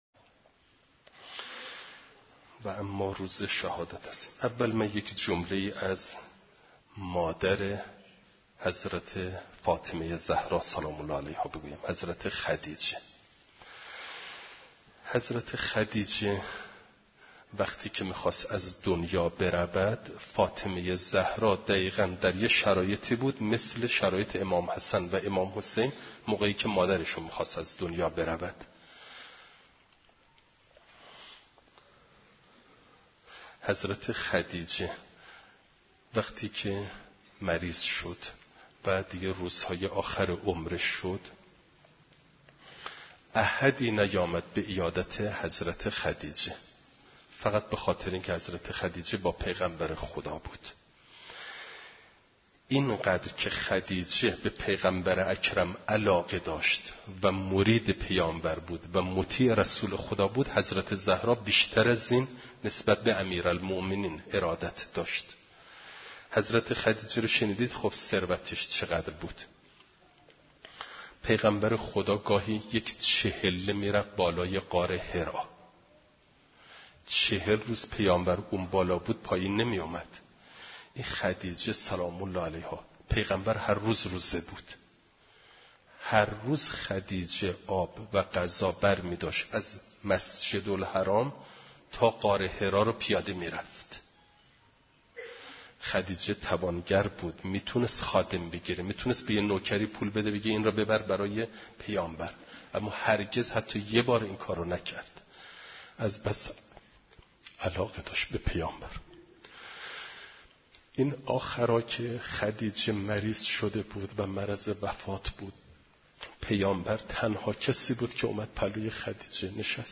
روضه حضرت خدیجه و حضرت زهرا سلام الله علیهما